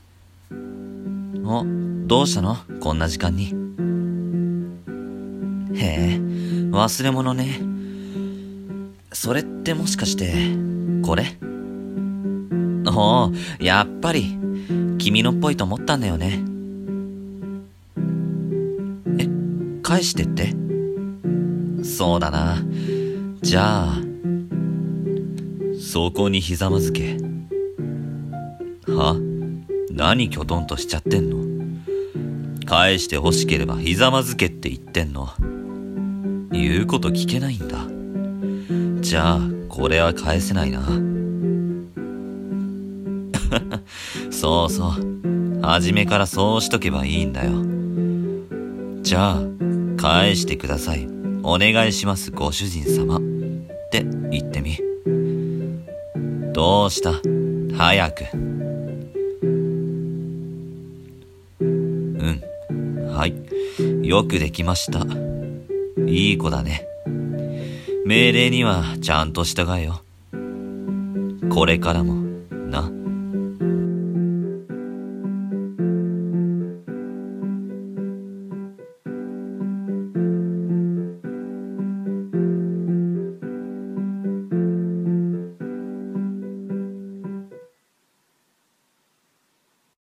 《朗読》ドSボイスをお聞かせください！！